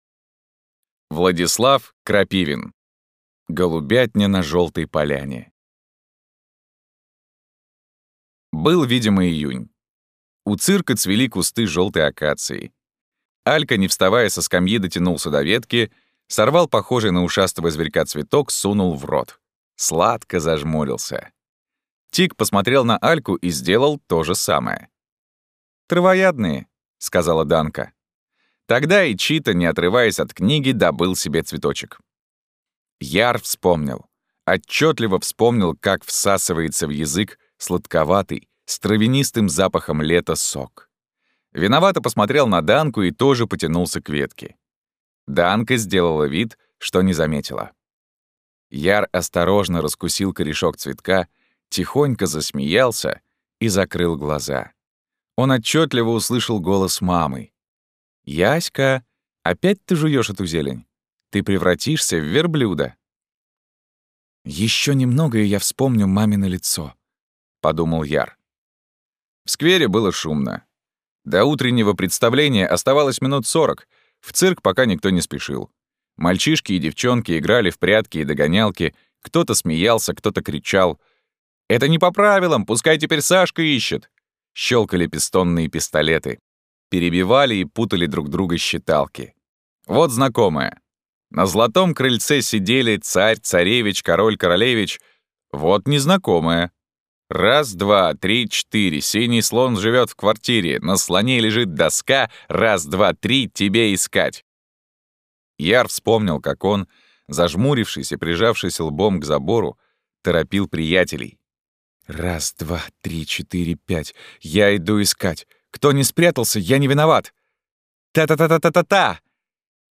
Aудиокнига Голубятня на желтой поляне